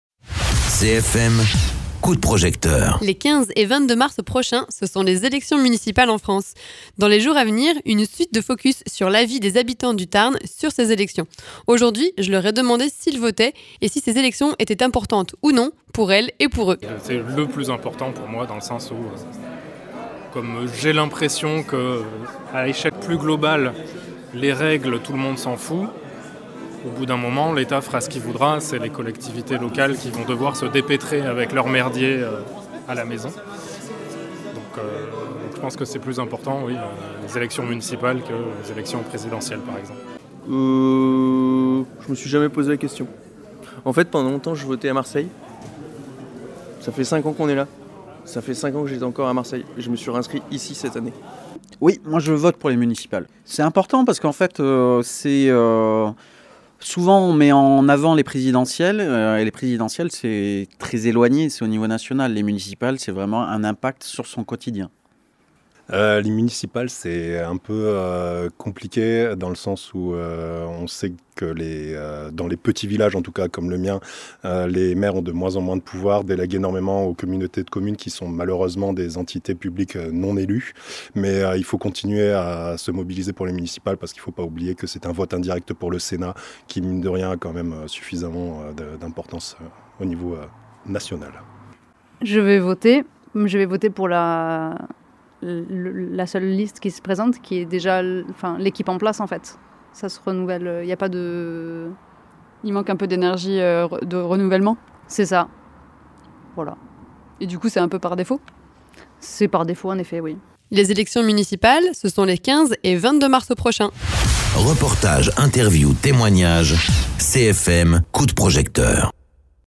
Interviews
En micro-trottoir, on s’intéresse à l’avis des habitant.e.s de nos territoires sur l’importance des élections municipales. Est-ce que voter pour ces élections est plus important que les élections nationales et européennes?
Invité(s) : Habitant.e.s du carmausin, du cordais et de l’albigeois